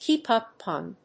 アクセントkèep úp on…